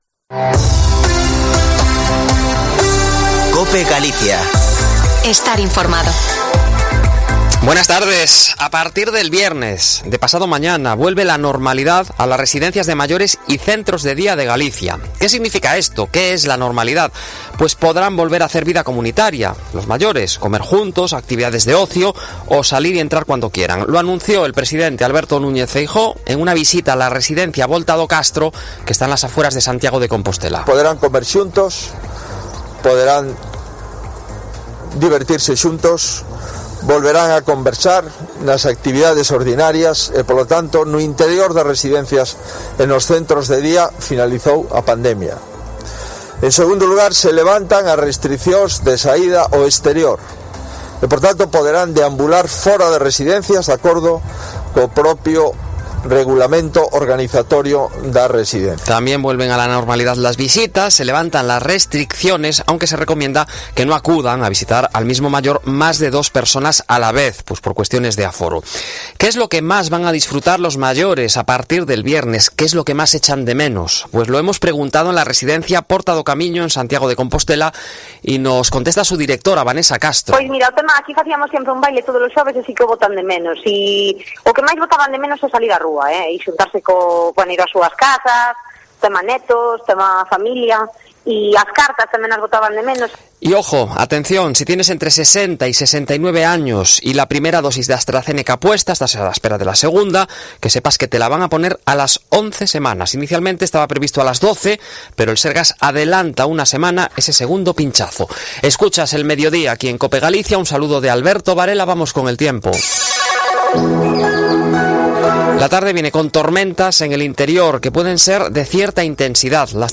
Informativo Mediodia en Cope Galicia 16/06/2021. De 14.48 a 14.58h